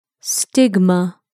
/ˈstɪgmə/